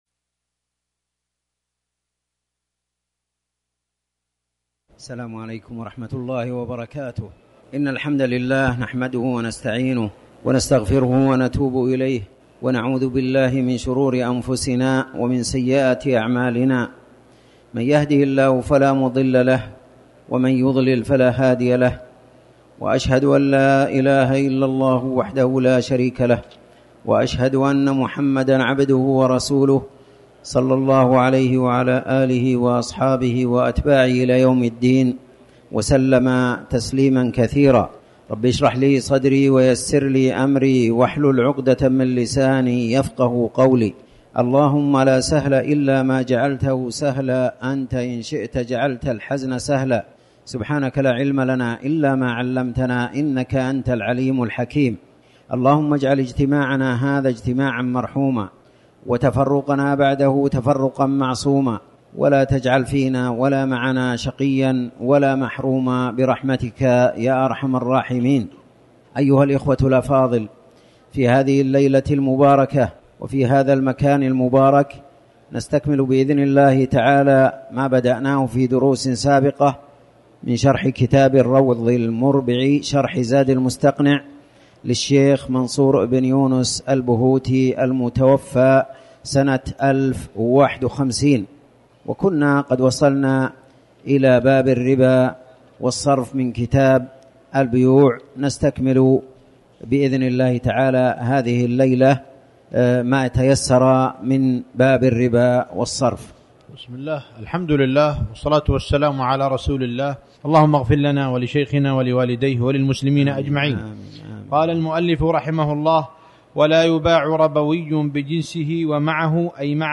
تاريخ النشر ٢٣ جمادى الأولى ١٤٤٠ هـ المكان: المسجد الحرام الشيخ